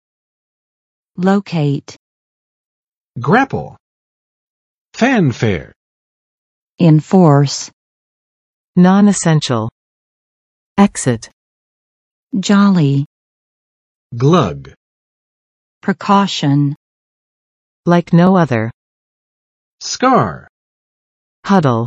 [ˋloket] v. 使……座落于